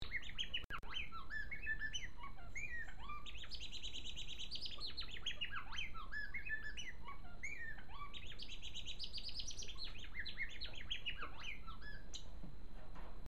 Singing ability:Somewhat pleasant
The male African Silverbill's song is a rising then falling pattern of rapidly repeated notes which forms a sweet trill. Songs vary noticeably among individual males.
African Silverbill cock singing (.mp3, .2 MB)